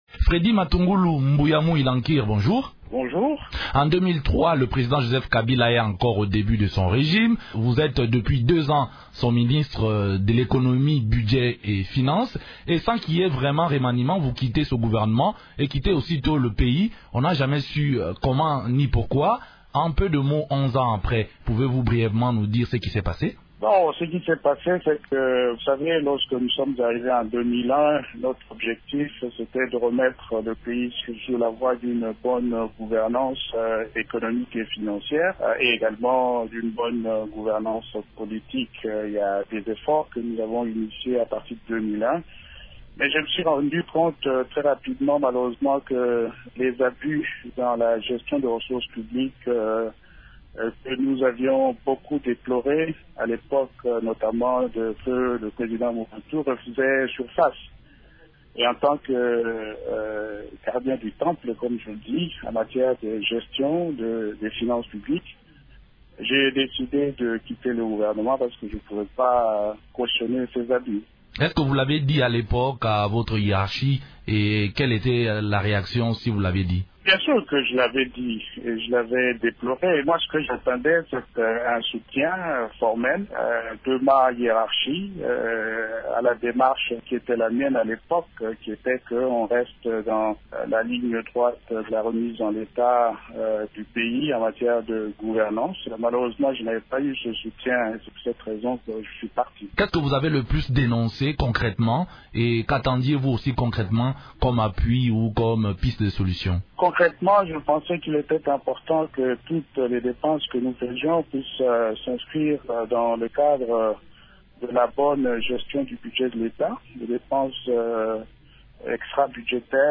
Freddy Matungula répond aux questions